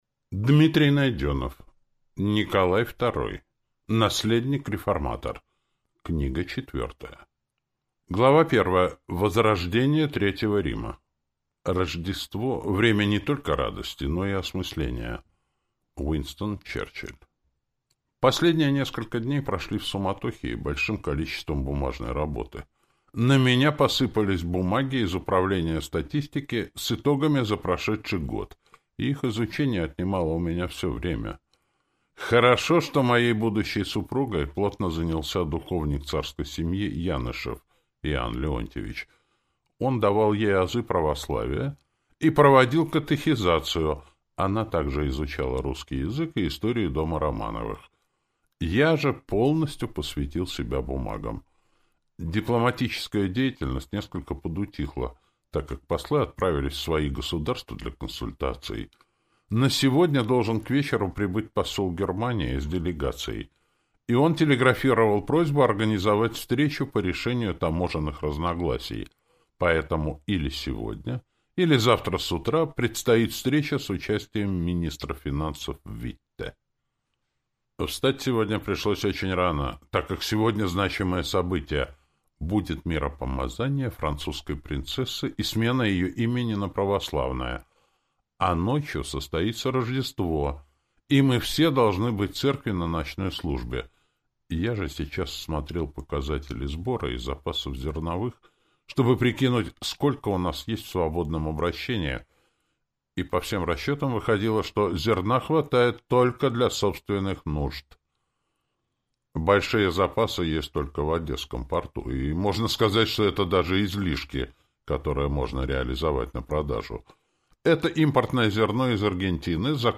Аудиокнига Николай Второй. Наследник-реформатор. Книга четвёртая | Библиотека аудиокниг